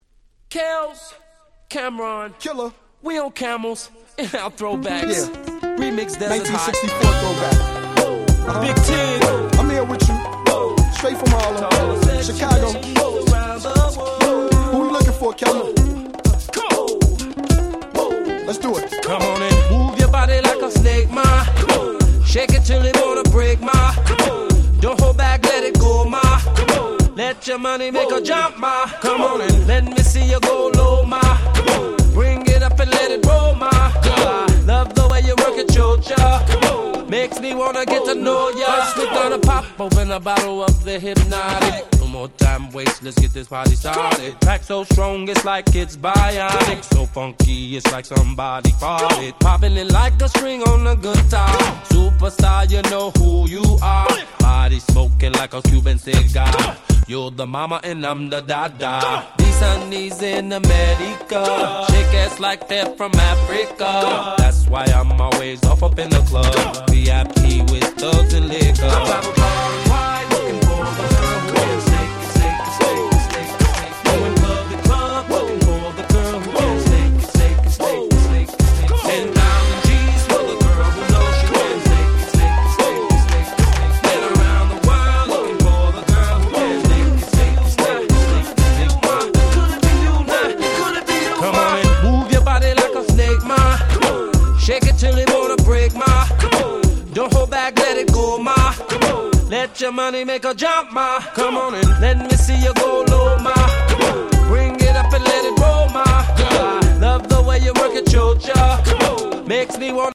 03' Super Hit R&B !!